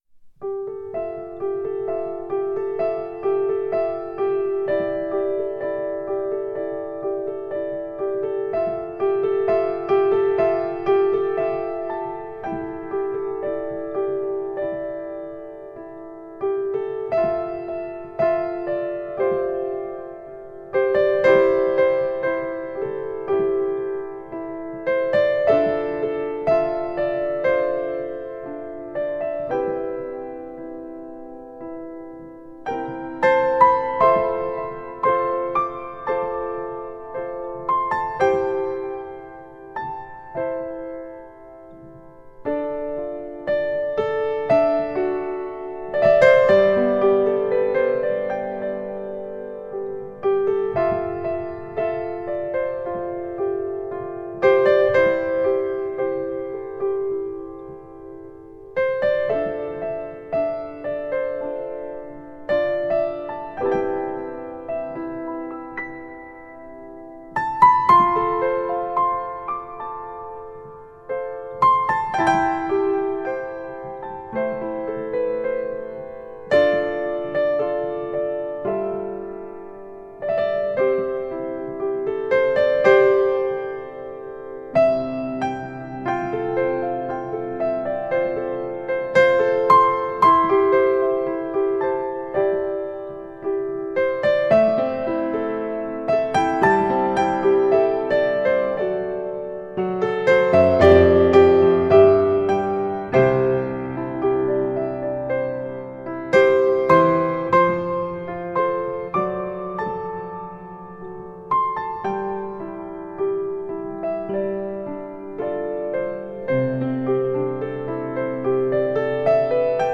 钢琴演奏
清澈幻美的音乐旋律，华语音乐的浪漫声音。